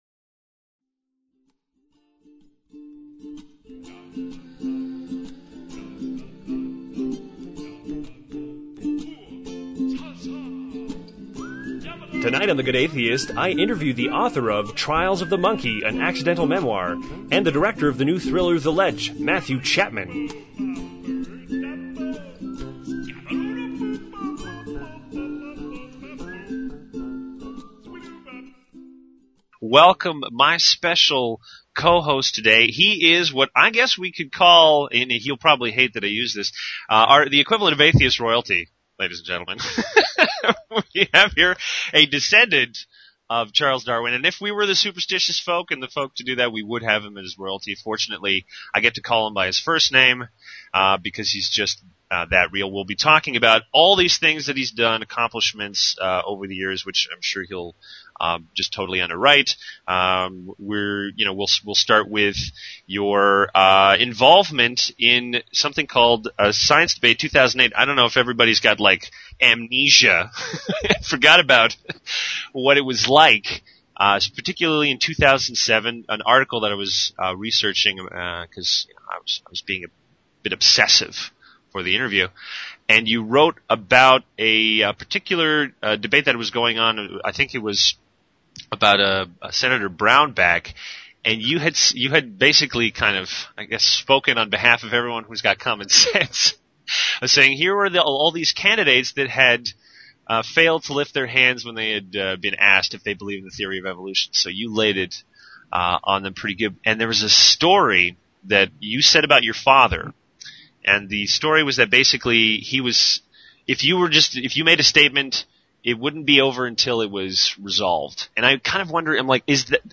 This week, I interview the author of Trials of the Monkey: an Accidental Memoir, 40 Days and 40 Nights and the director of the new movie The Ledge: Matthew Chapman.